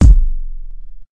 Kick (2).wav